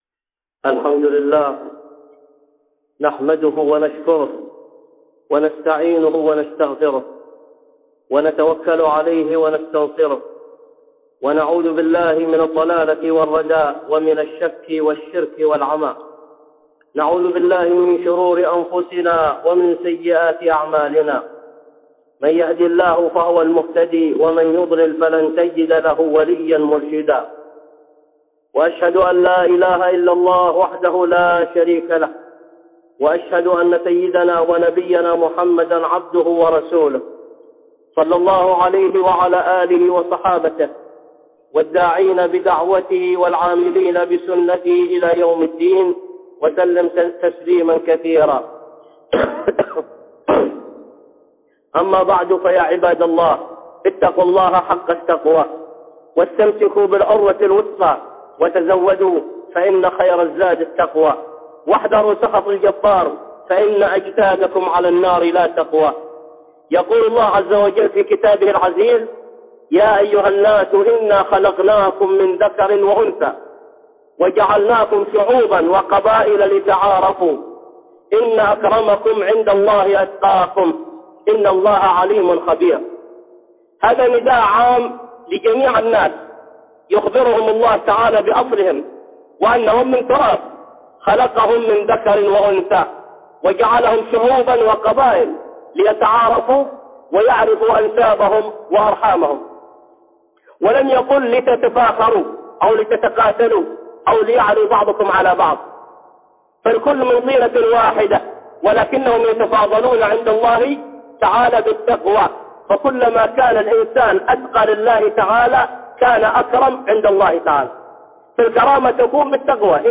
(خطبة جمعة) التحذير من القتال بين القبائل وأثره على الأخوة الإسلامية 1